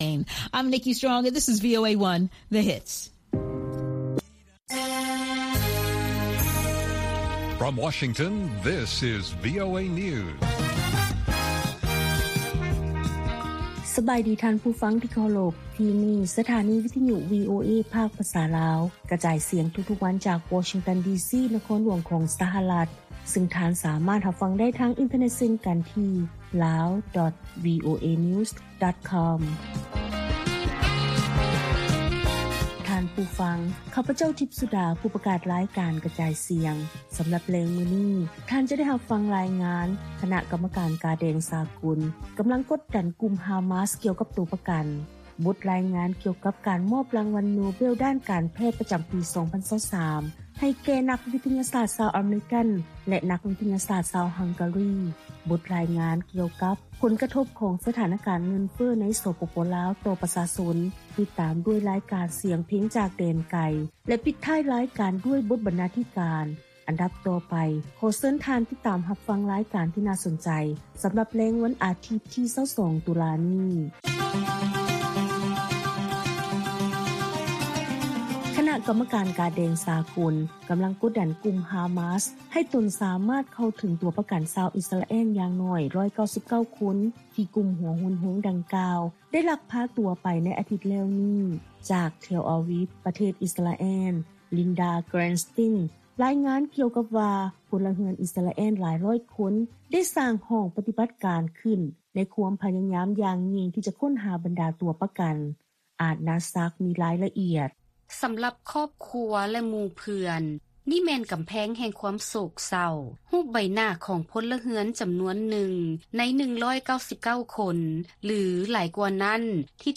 ລາຍການກະຈາຍສຽງຂອງວີໂອເອ ລາວ: ອາສາສະໝັກອິສຣາແອລ ຊອກຫາພົນລະເຮືອນ ລວມເຖິງເດັກນ້ອຍ ແລະ ຜູ້ສູງອາຍຸ ທີ່ກຸ່ມຮາມາສ ຈັບເປັນໂຕປະກັນ